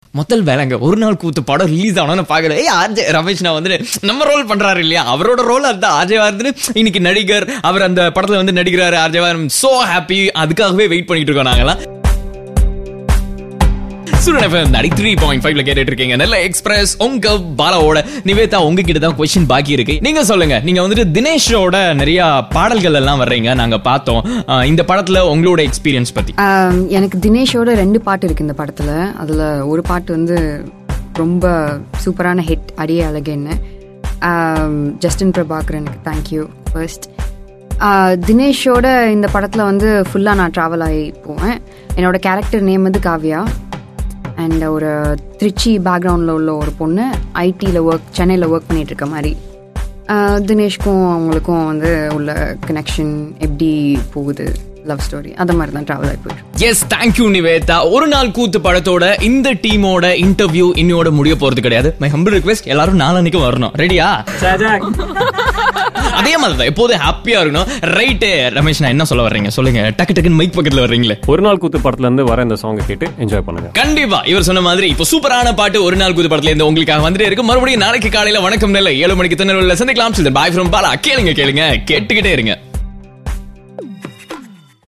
Interview oru nal koothu